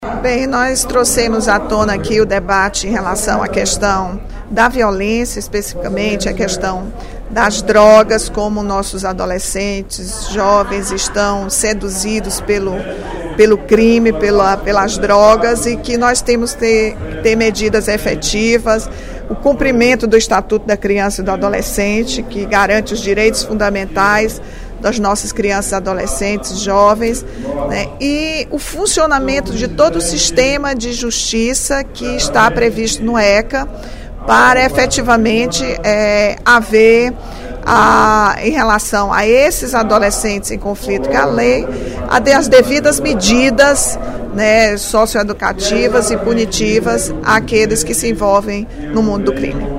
A deputada Rachel Marques (PT) chamou atenção, durante o primeiro expediente da sessão plenária desta quarta-feira (12/06), para uma série de matérias publicadas no jornal Diário do Nordeste esta semana, que aborda a situação dos menores infratores no Ceará.